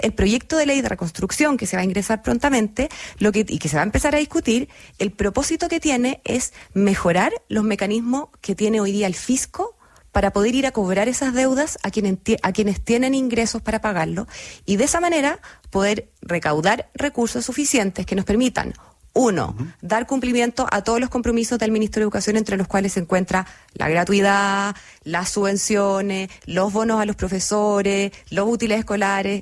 En entrevista con ADN Hoy, la secretaria de Estado fue enfática en descartar cambios que afecten a quienes actualmente cuentan con gratuidad.